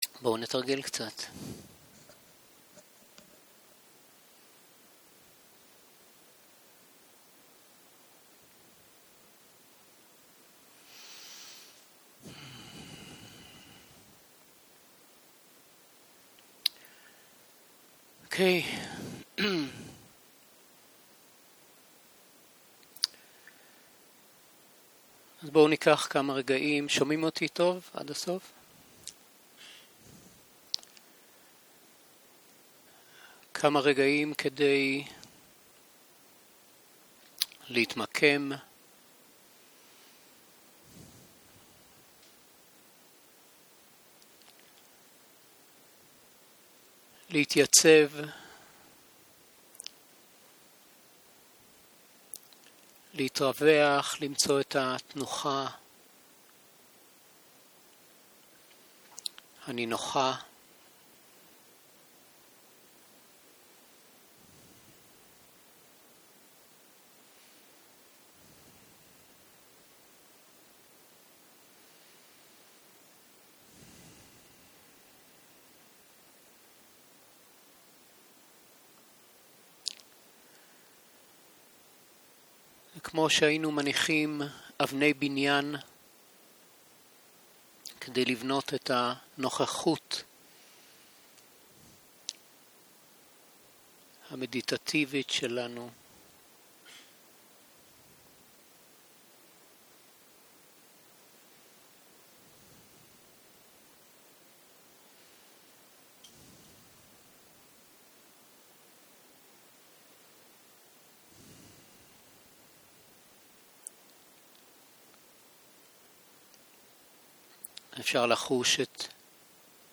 יום 2 - צהרים - מדיטציה מונחית - הקלטה 3
סוג ההקלטה: מדיטציה מונחית